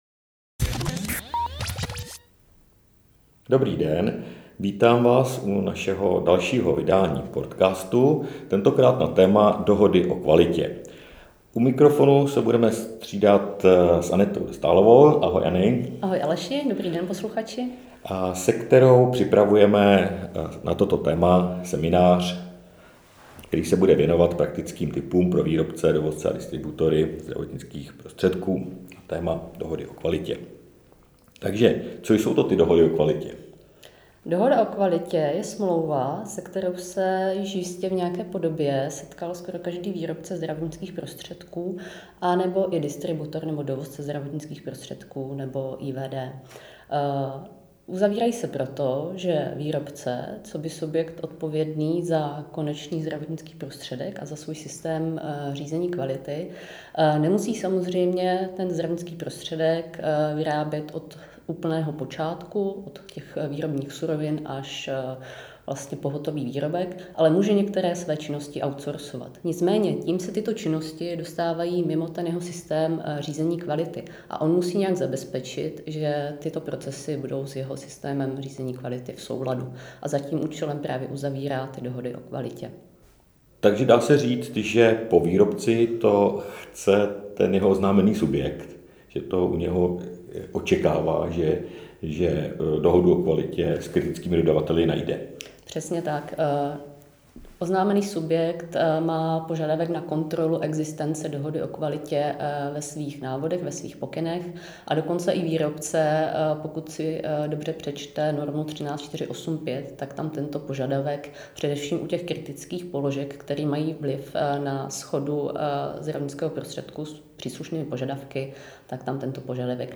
Cílem cca jedenácti minutového rozhovoru je posluchačům stručně vysvětlit hlavní důvody pro jejich uzavírání, na které navážeme v rámci podzimního webináře Dohody o kvalitě – nutné zlo nebo obchodní výhoda?